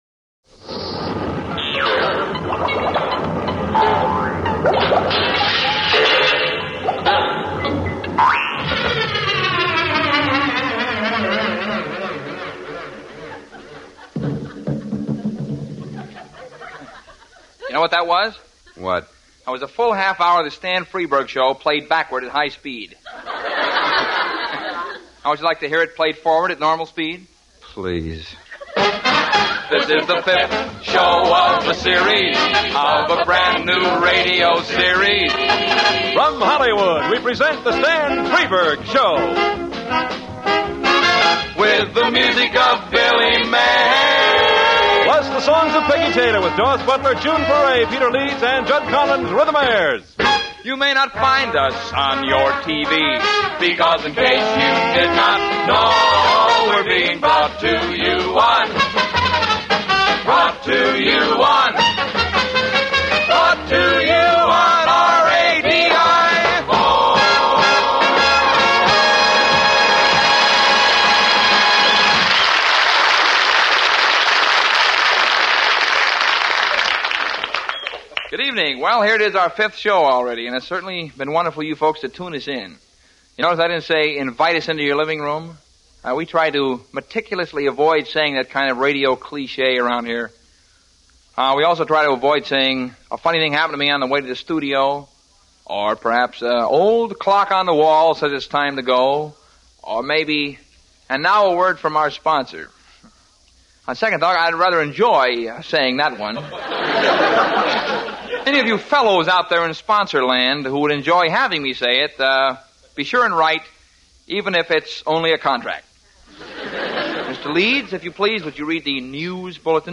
In the late 1950s he produced his own radio program, a sort of last-gasp of Network radio in 1957.
Irreverent, surreal humor and a large slice of satire thrown in, Stan Freberg became a legendary figure in Popular Culture; one whose work has an air of timelessness about it, some 60 years later. So as tribute the comic genius of Stan Freberg, here is an episode of one of those Stan Freberg shows for CBS Radio – first aired on November 8, 1957.